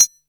perc 4.wav